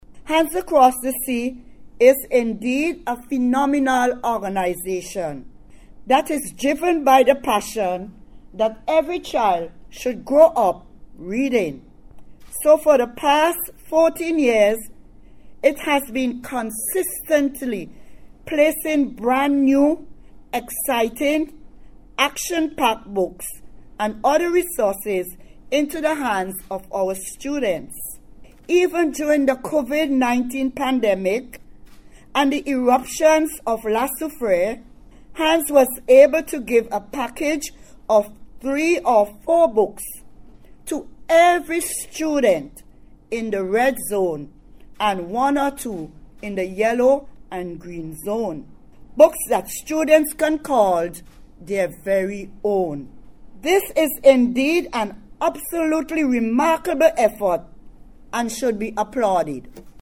The books were handed over during a short ceremony held at the National Public Library in Kingstown this morning.